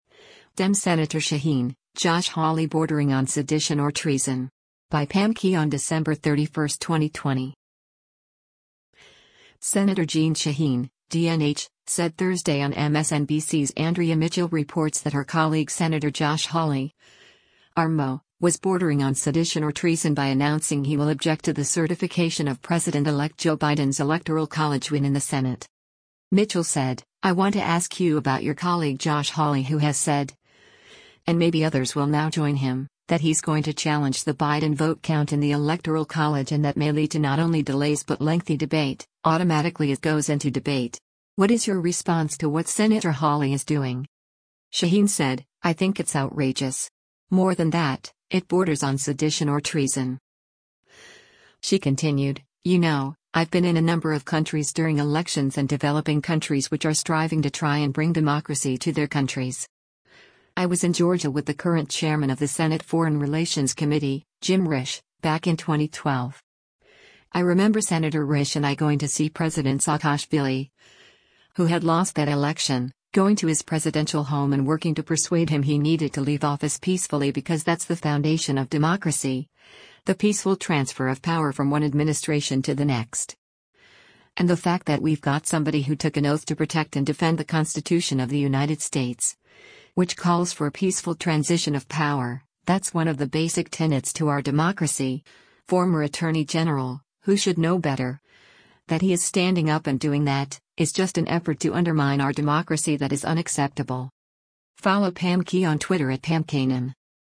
Senator Jeanne Shaheen (D-NH) said Thursday on MSNBC’s “Andrea Mitchell Reports” that her colleague Sen. Josh Hawley (R-MO) was bordering on “sedition or treason” by announcing he will object to the certification of President-elect Joe Biden’s electoral college win in the Senate.